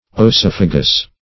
oesophagus \oe*soph"a*gus\, n., oesophageal \oe`so*phag"e*al\,